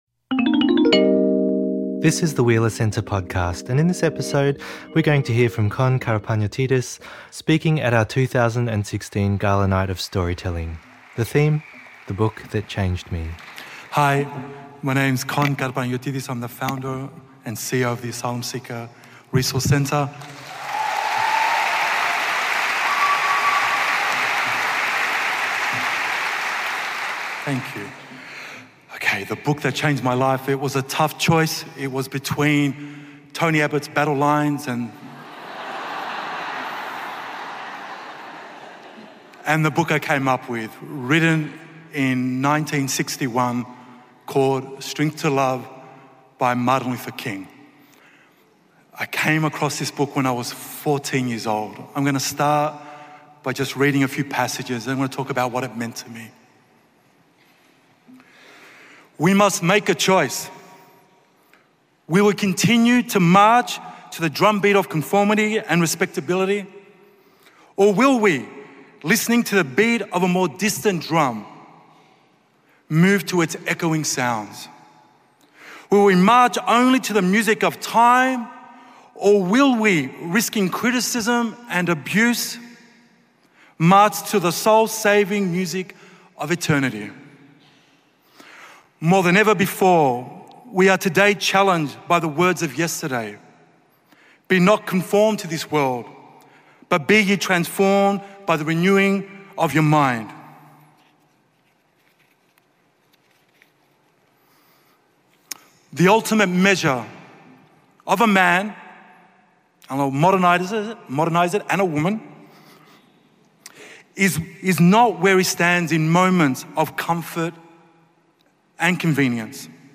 at the Wheeler Centre Gala Night of Storytelling 2016